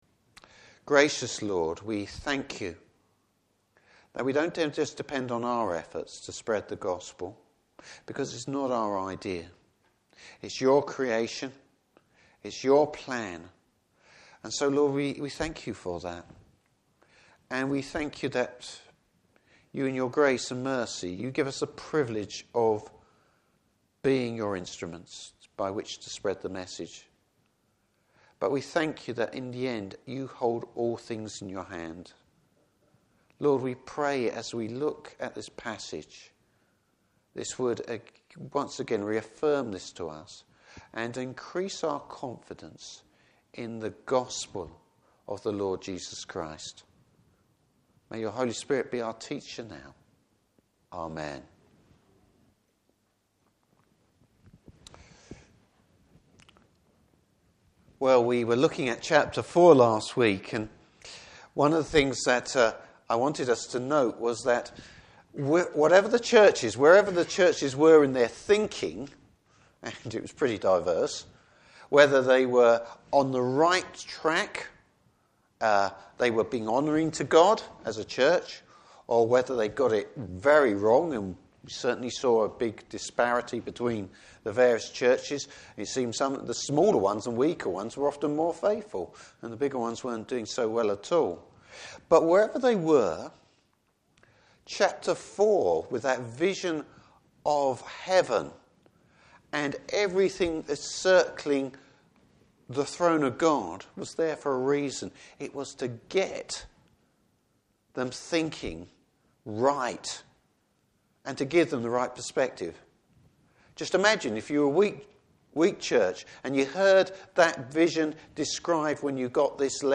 Service Type: Evening Service The Lord’s control over the World’s history!